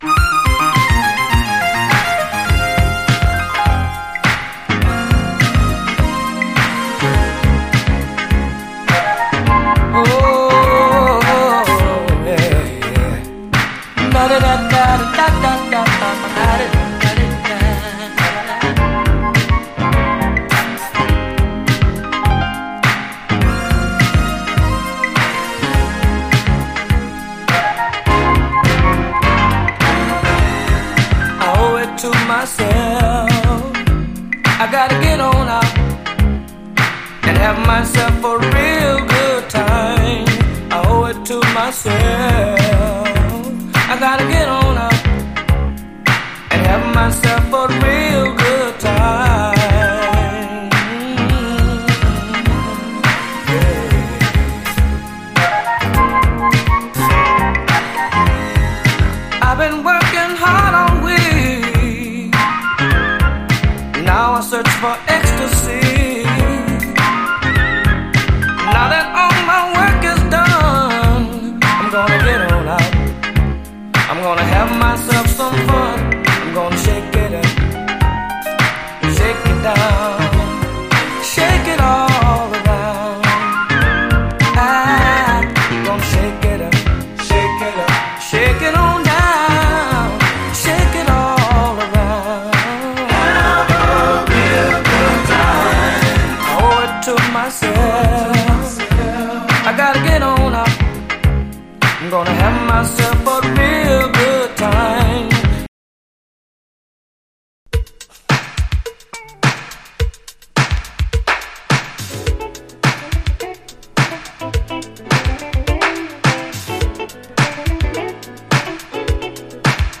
SOUL, 70's～ SOUL, DISCO
最高ビューティフル・メロウ・モダン・ソウル
エレガント・ブギー・ファンク